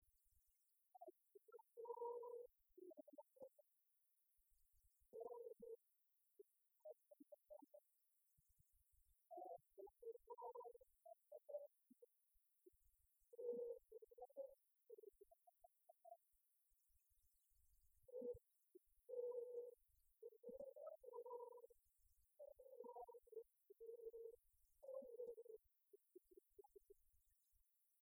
Concert de la chorale des retraités
Pièce musicale inédite